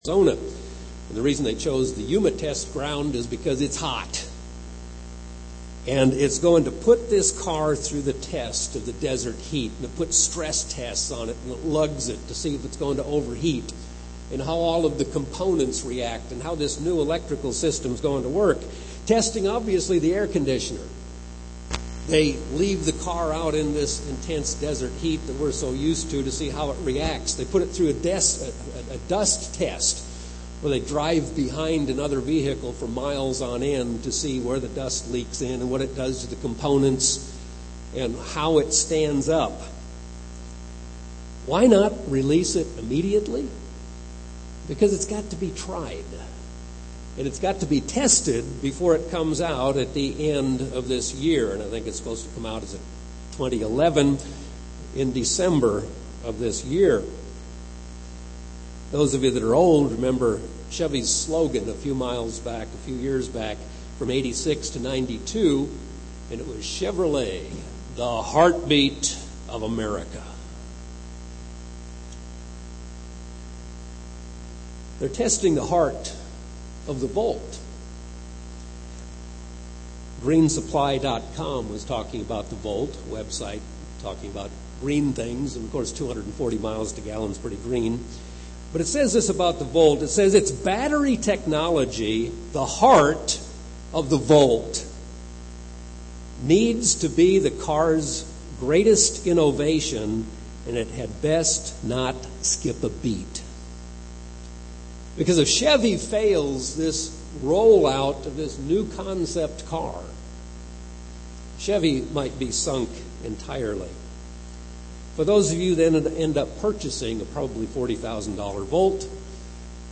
God needs to know if we have the attitude of a wrong Korah or a right On UCG Sermon Studying the bible?